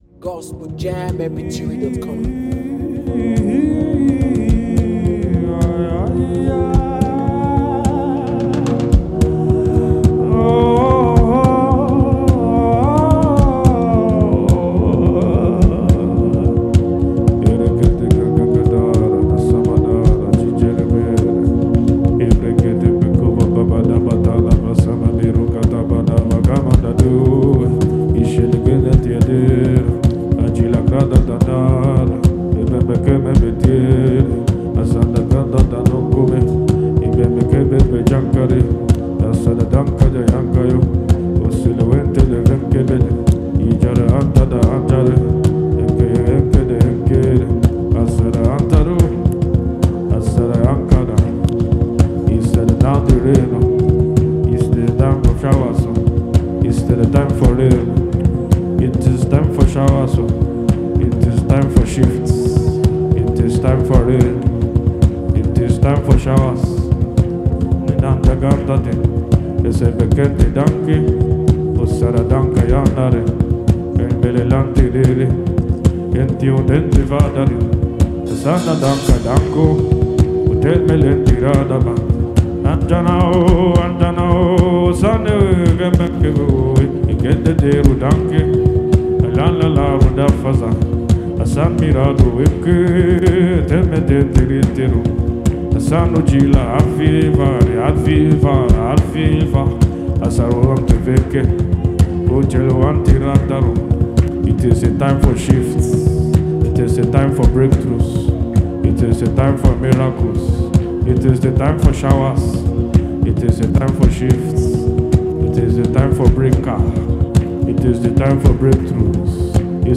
Get soaked with this chant of fire.